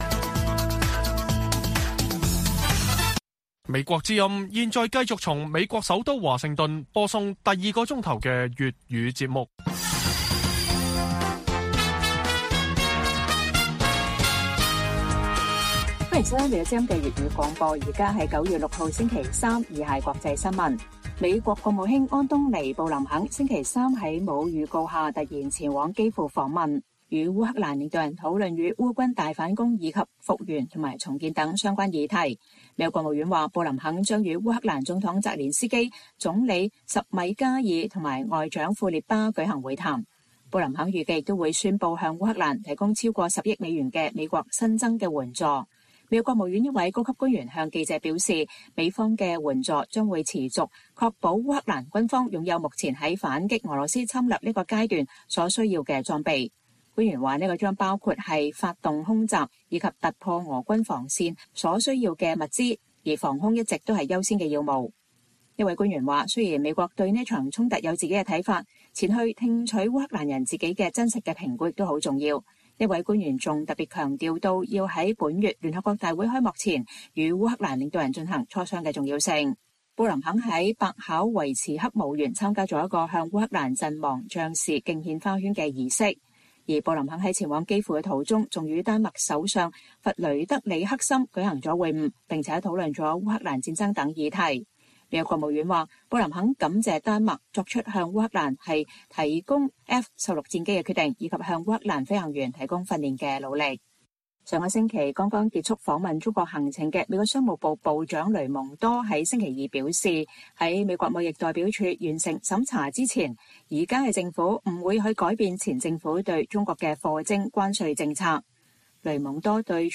粵語新聞 晚上10-11點: 美國國務卿布林肯突訪基輔，討論烏軍大反攻及美國對烏援助